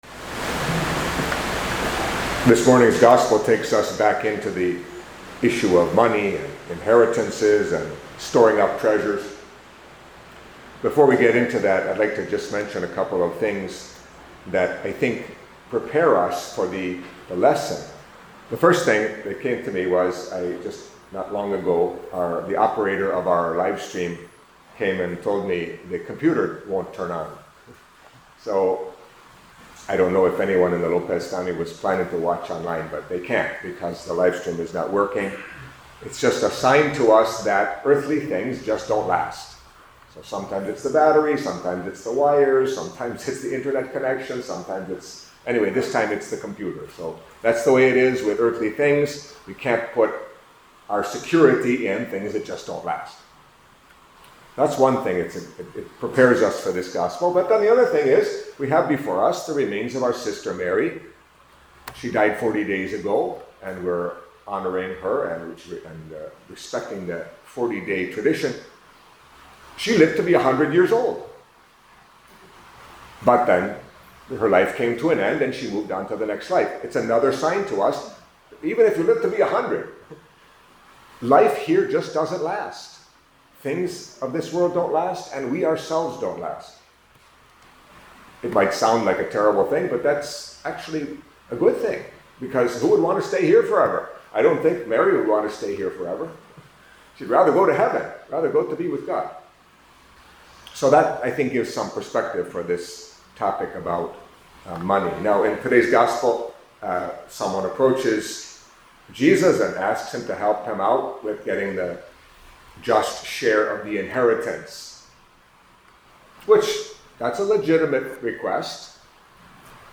Catholic Mass homily for Monday of the Twenty-Ninth Week in Ordinary Time